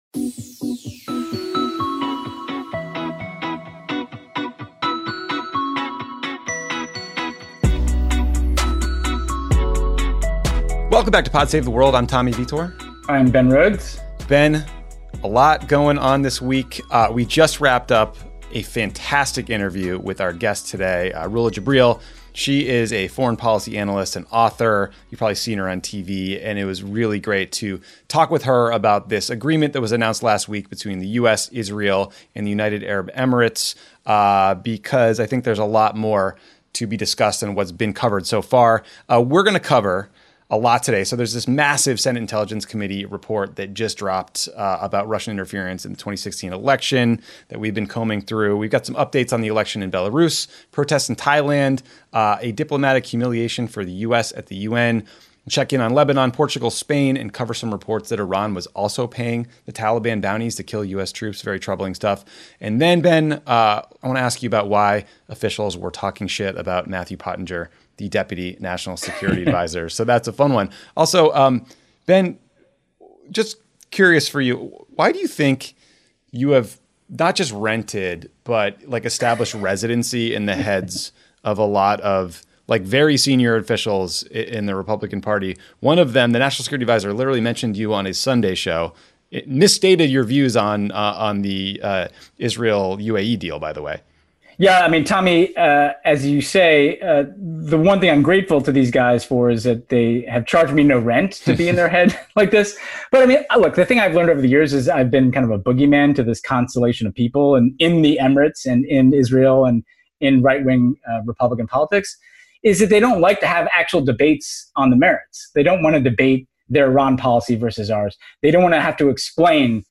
Foreign policy analyst and author Rula Jebreal joins to discuss the US-Israel-UAE diplomatic agreement announced last week.